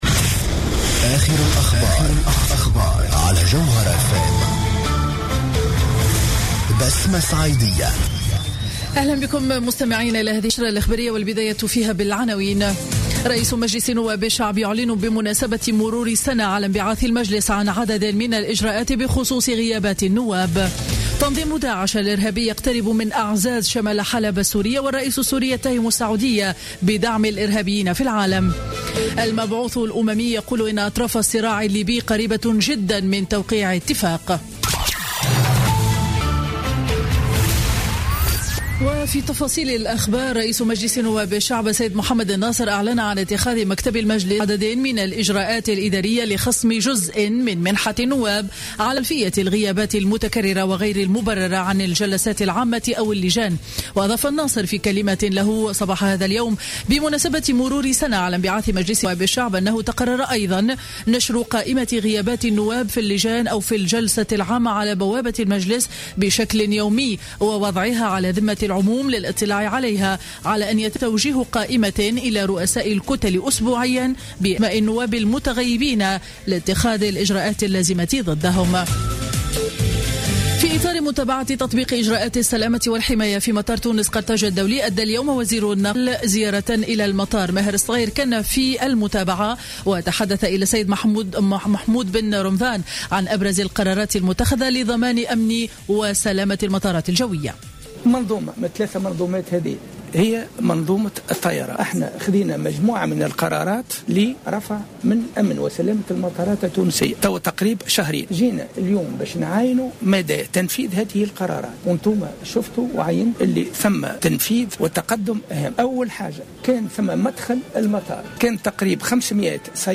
نشرة أخبار منتصف النهار ليوم الأربعاء 2 ديسمبر 2015